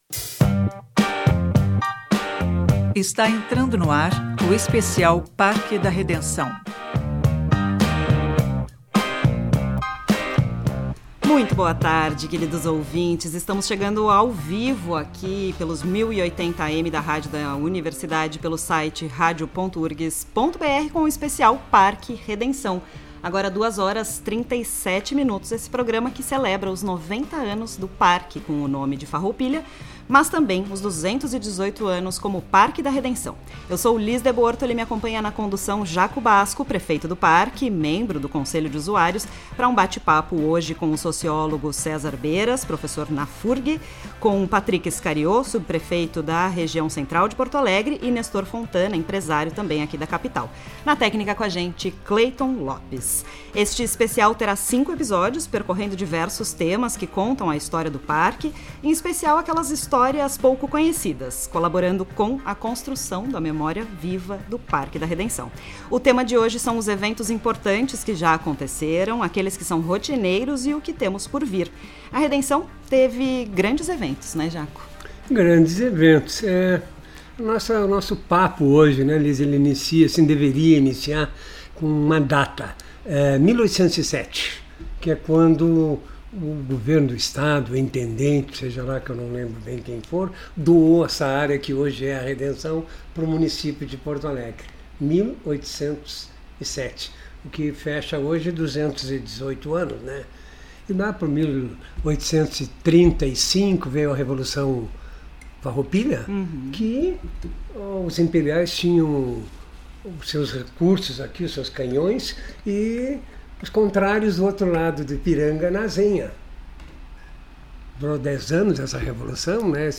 ESPECIAL REDENÇÃO AO-VIVO 28-11-25.mp3